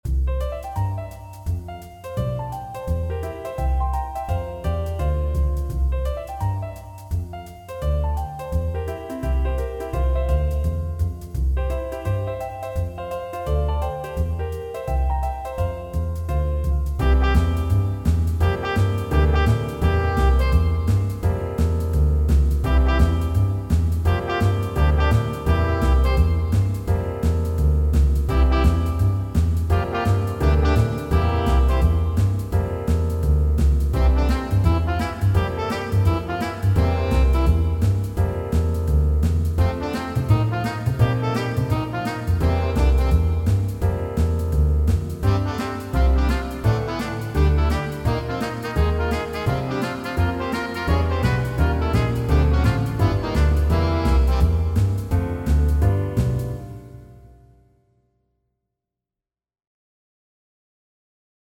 All audio files are computer-generated.
Trumpet, Sax, Trombone, Piano, Bass, Drums